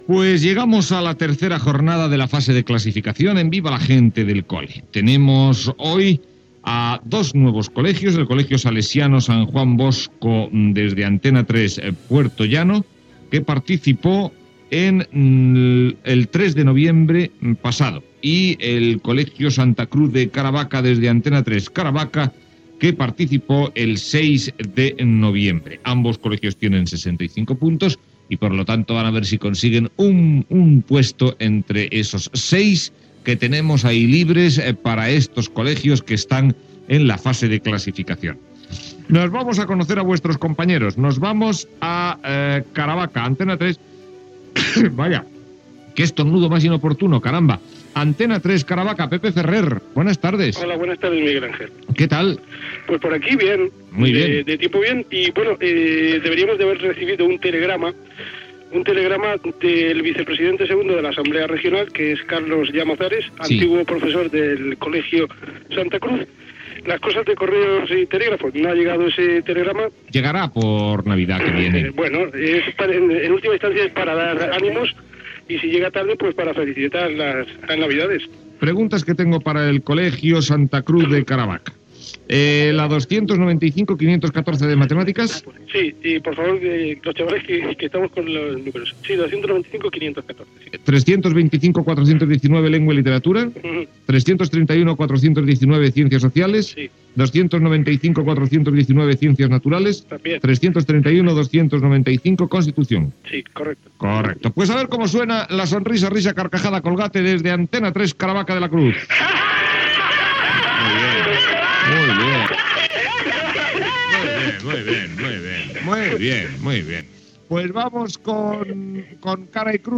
Concurs per a estudiants.
Entreteniment